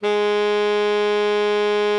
bari_sax_056.wav